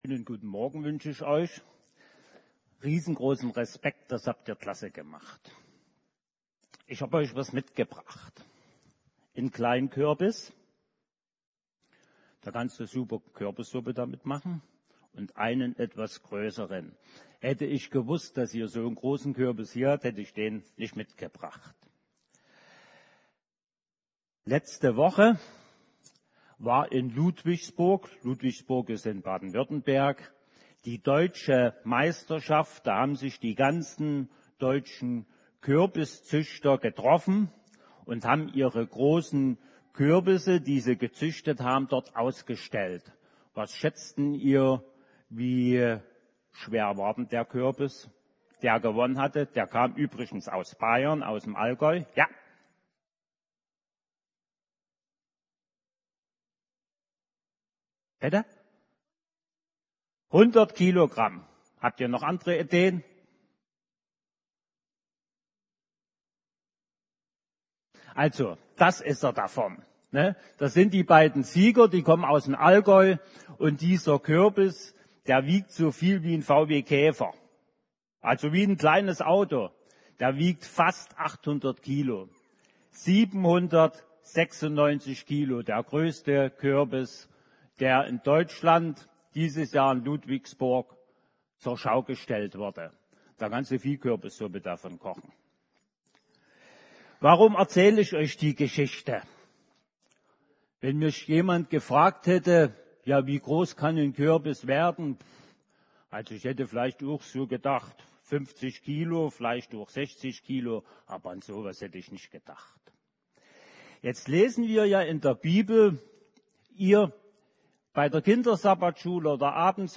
Erntedank 2022
Predigten